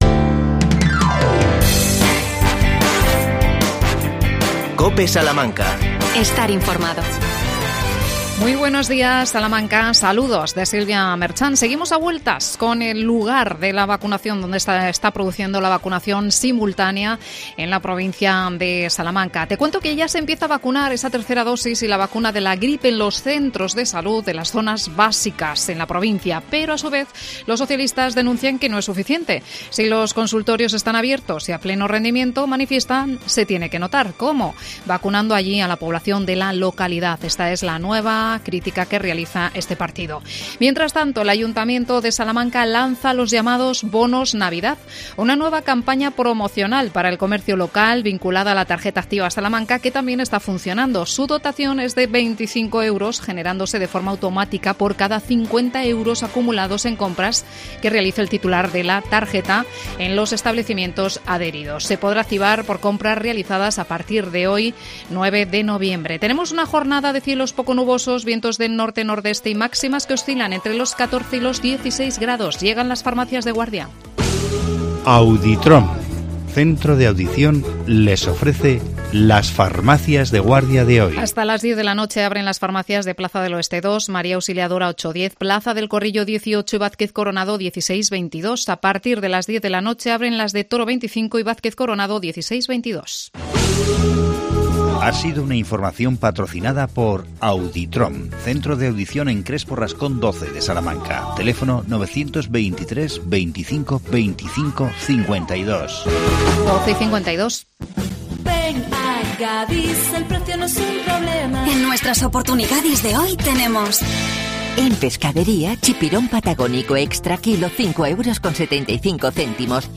AUDIO: Entrevista al concejal de Protección Ciudadana Fernando Carabias. El tema: refuerzo vigilancia policial en el ocio nocturno.